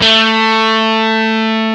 DIST GT1-A2.wav